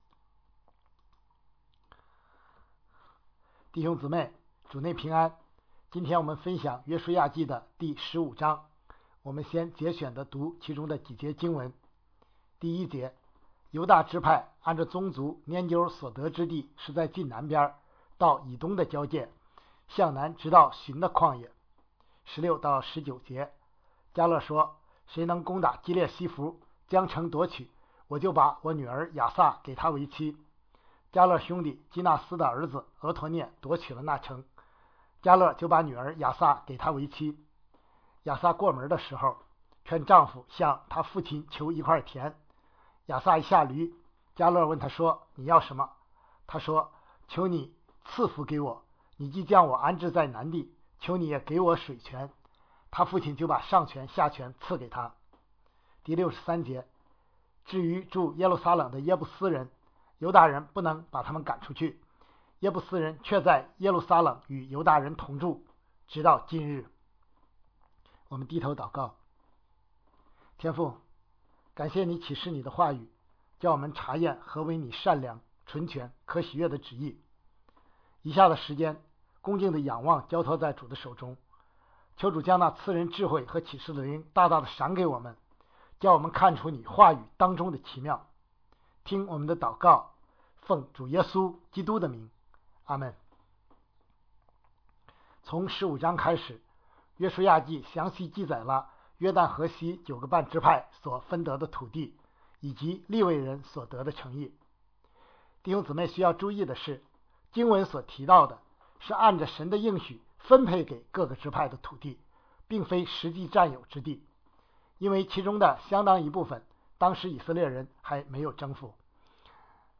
承受那地为业：你是族大人多并且强盛，能把他们赶出去——2014年6月29日主日讲章